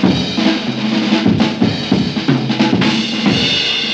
JAZZ BREAK15.wav